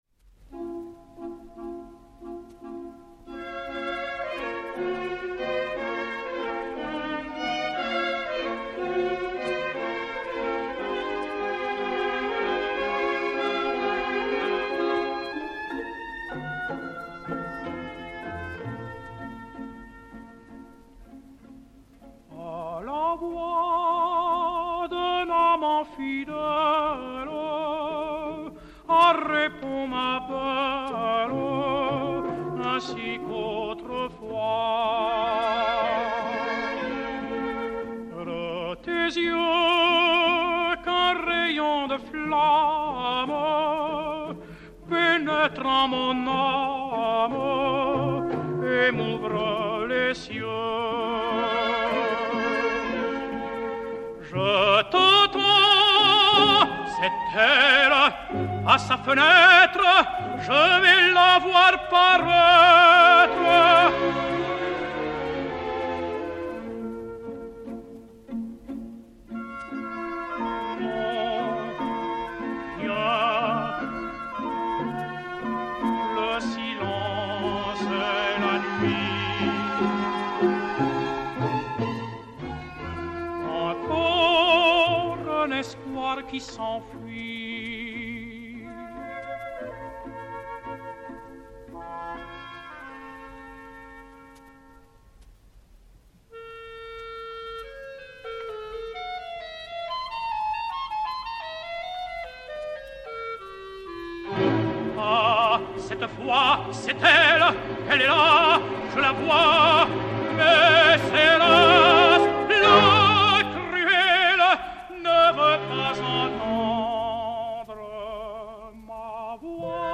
Sérénade "A la voix d'un amant fidèle"
Orchestre